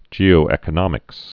(jēō-ĕkə-nŏmĭks, -ēkə-)